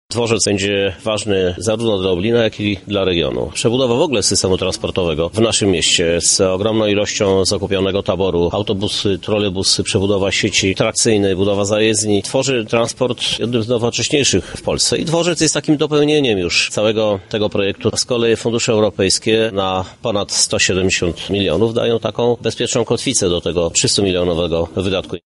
– mówi prezydent miasta, Krzysztof Żuk i dodaje że równie istotnym elementem nowej infrastruktury jest dalsza rozbudowa ulicy Lubelskiego Lipca 80, która ma być główną osią komunikacyjną w tej części miasta.